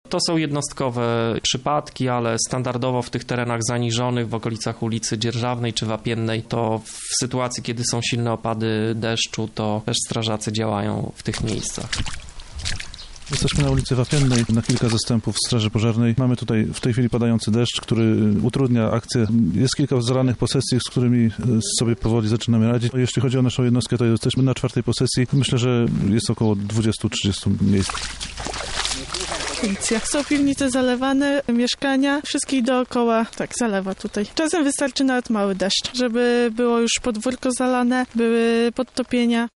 Również mieszkańcy Lublina musieli uporać się z zalanymi piwnicami. Na miejscu była nasza reporterka.